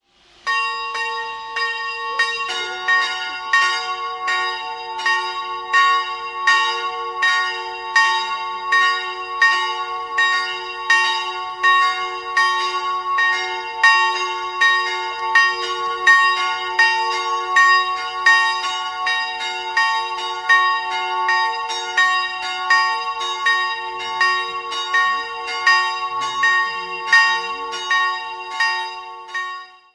Der Turm wurde mit Zwiebelbekrönung wieder aufgebaut. 2-stimmiges Große-Terz-Geläute: ges''-b'' Nähere Daten liegen nicht vor.